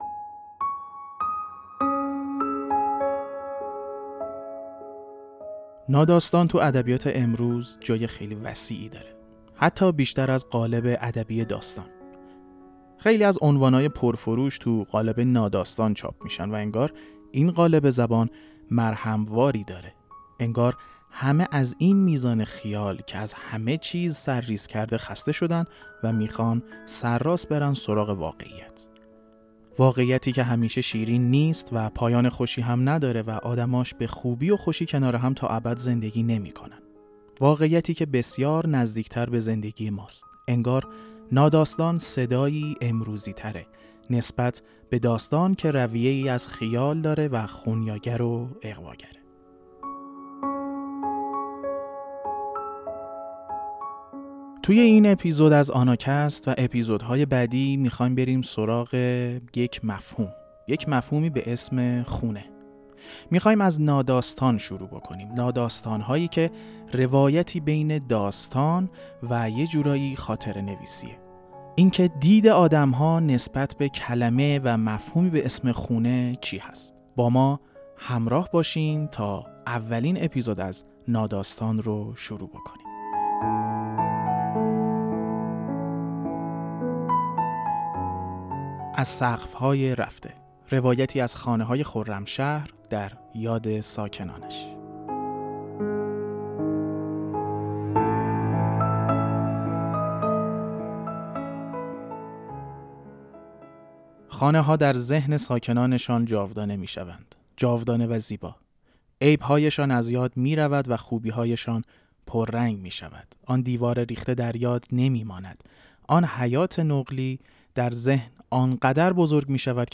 خانه، قسمت اول: سری اول خوانش‌های ناداستان آناکست به سراغ مفهوم «خانه» رفته است.